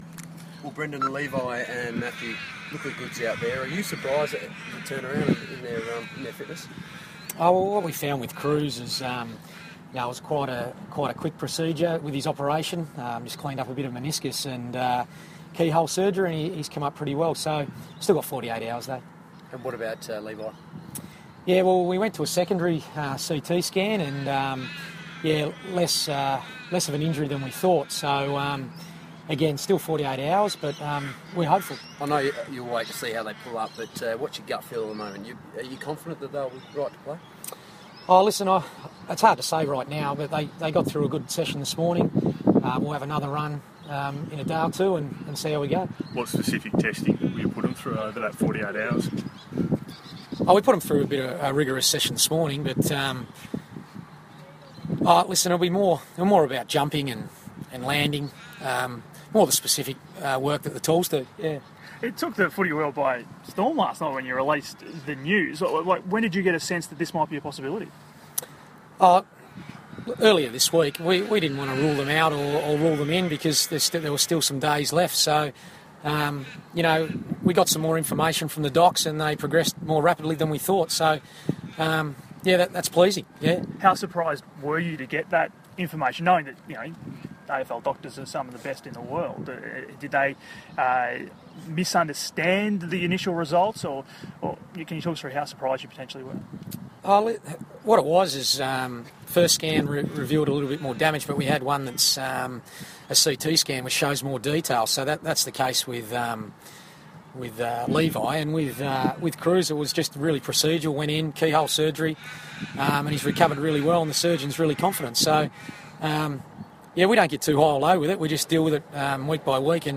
Brendon Bolton press conference - May 27
Carlton coach Brendon Bolton fronted the media on Friday morning ahead of the Blues' upcoming clash against Geelong.